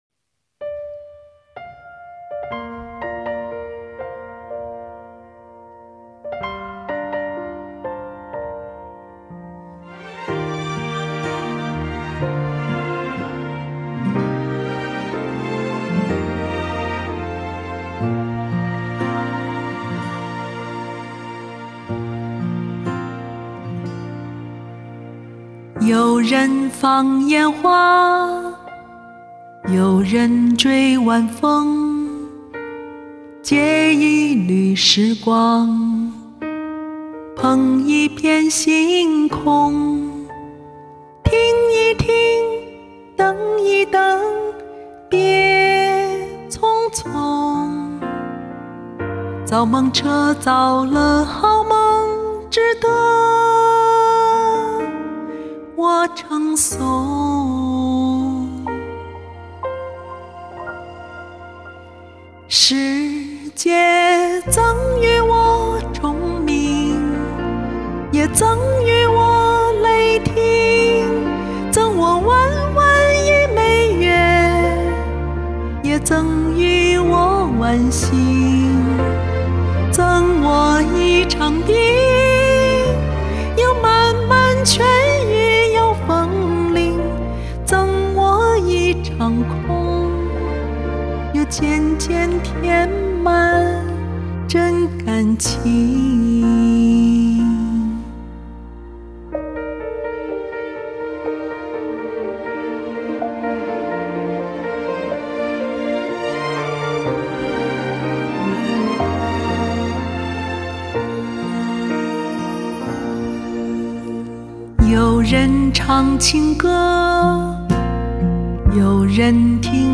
輕柔，自然，流入心底的歌聲！聲音和吐字都很棒！
歌聲迷人，一如既往的溫潤、端莊、正直，很喜歡，好禮物。